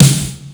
rock snare.wav